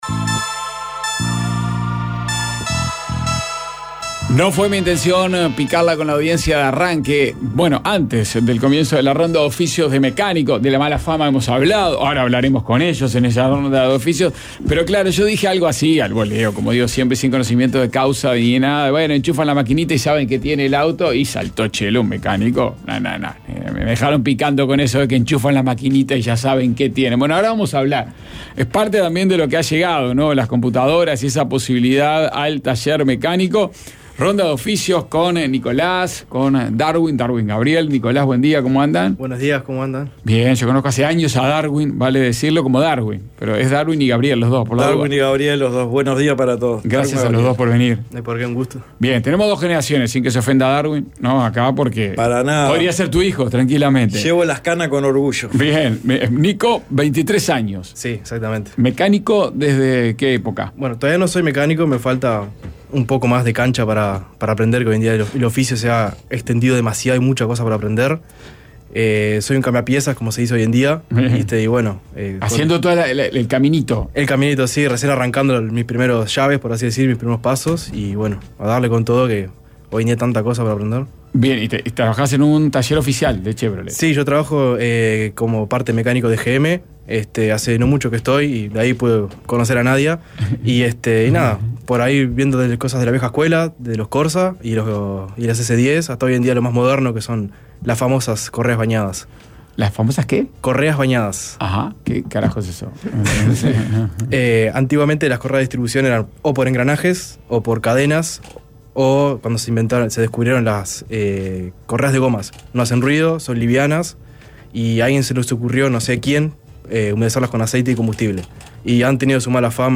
Dos mecánicos, un oficio con mala fama y muchas historias